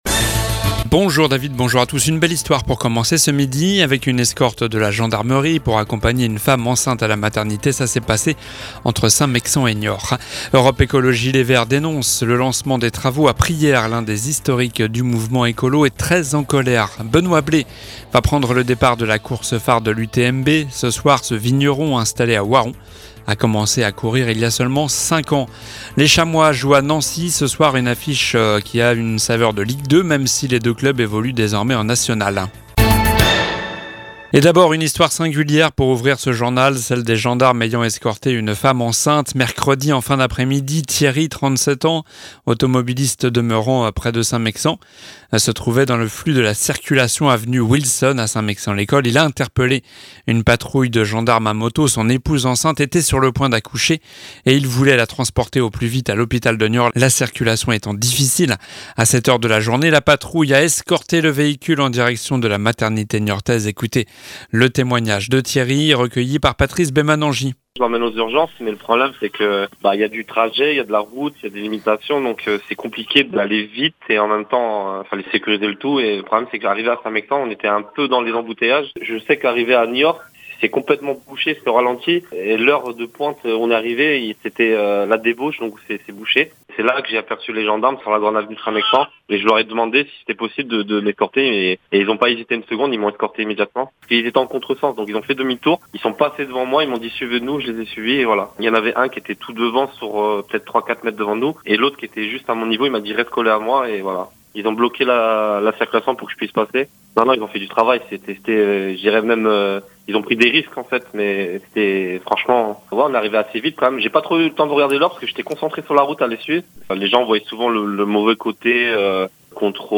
Journal du vendredi 1er septembre (midi)